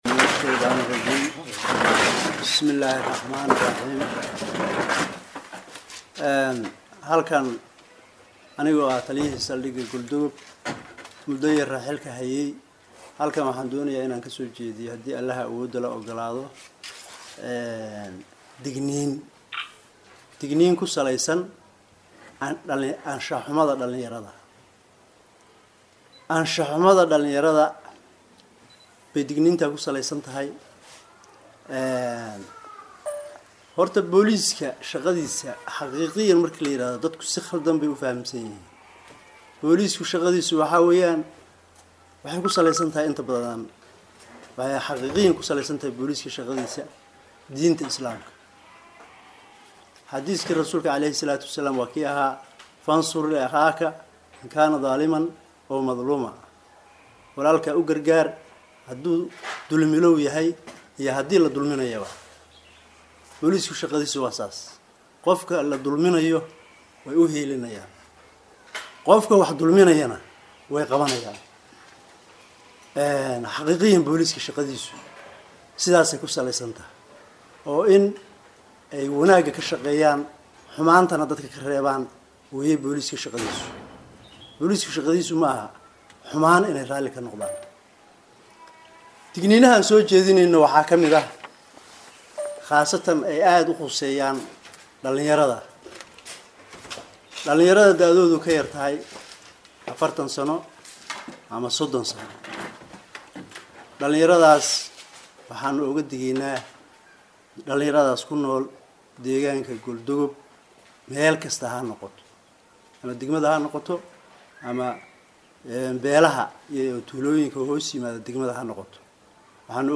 Taliyaha-cusub-ee-maagaalada-galdogob-ayaa-waxa-uu-shir-jaraaid-ku-qabty-magaalada-galdogob-2.mp3